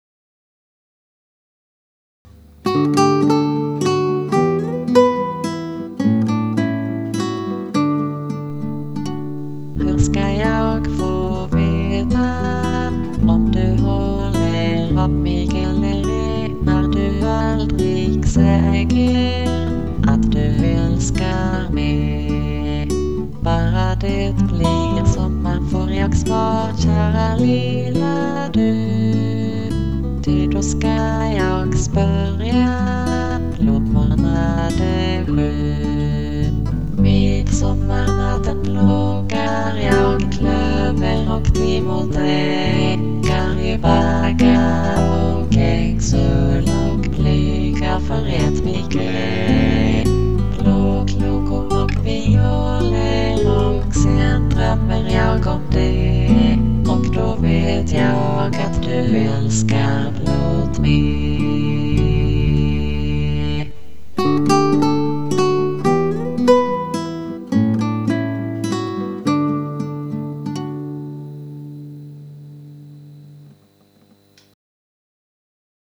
Well-known love song
synthetic folk singer twins
Traditional ballad